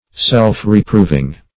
Search Result for " self-reproving" : The Collaborative International Dictionary of English v.0.48: Self-reproving \Self`-re*prov"ing\, a. Reproving one's self; reproving by consciousness of guilt.